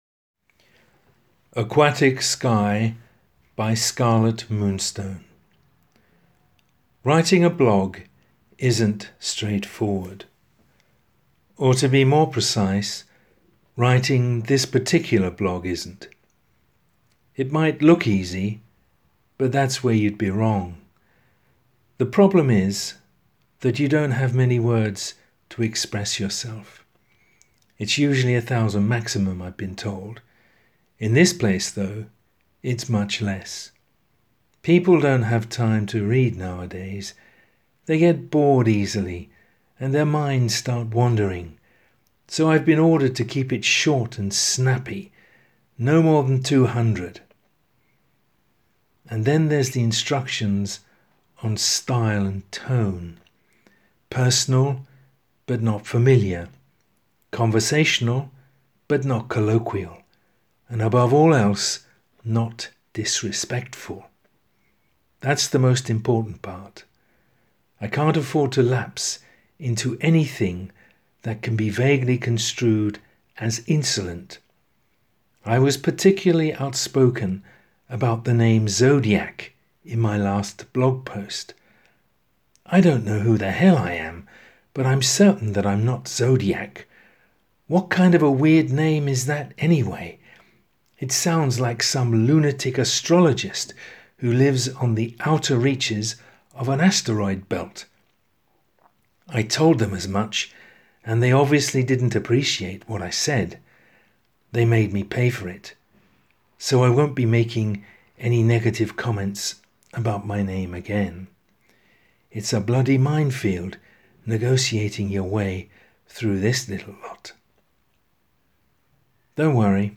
Spoken text